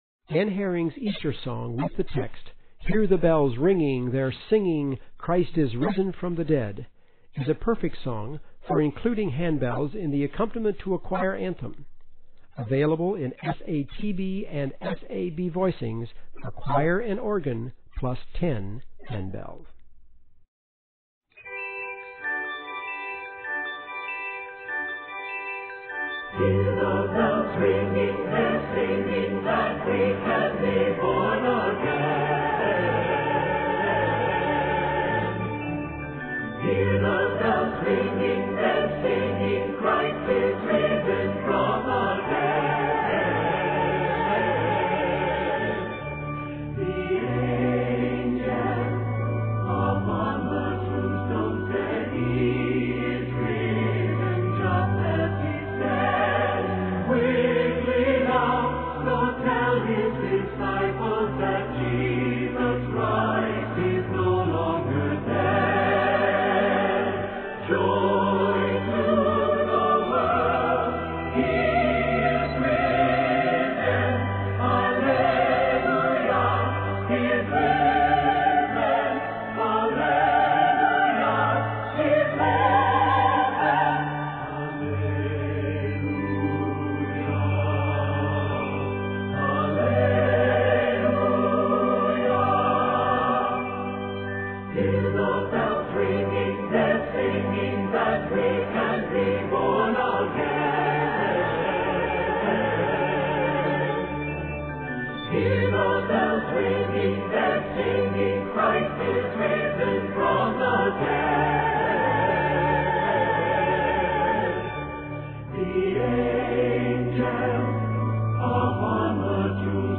SAB version
choral setting. The handbell part for 10 bells is included.